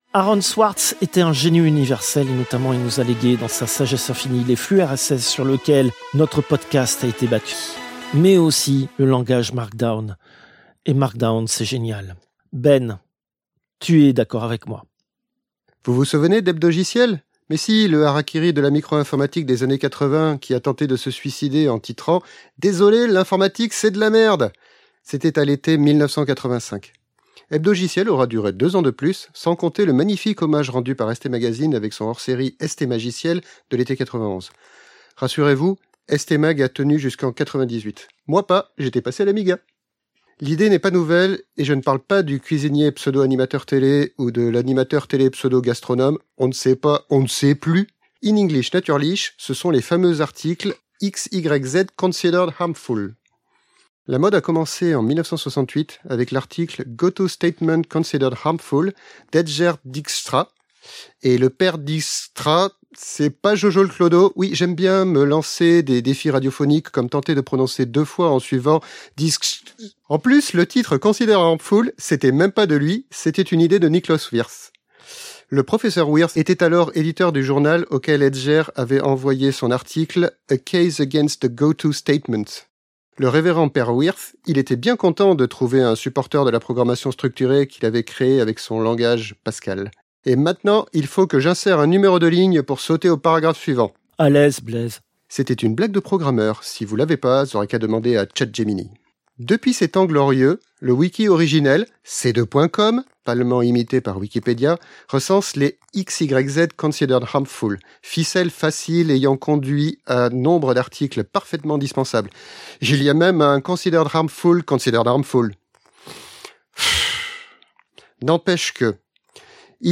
Extrait de l'émission CPU release Ex0222 : lost + found (rentrée 2025) seconde partie).